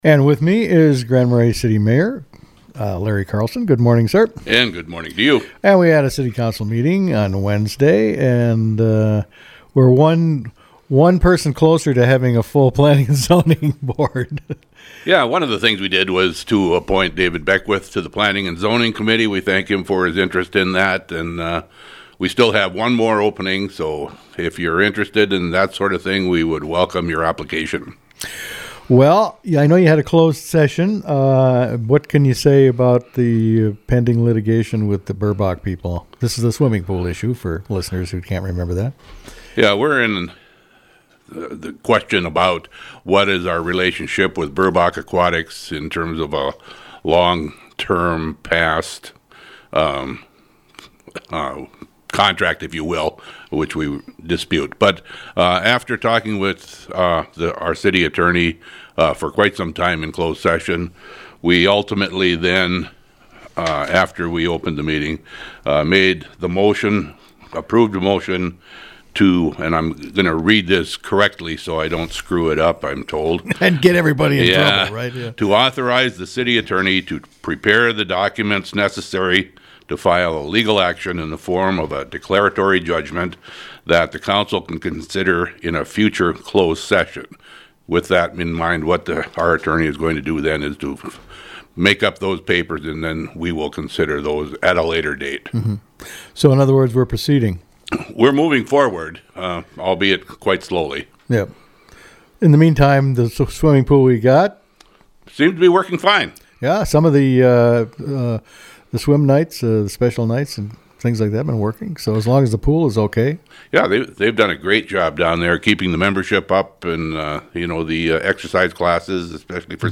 spoke with Mayor Larry Carlson.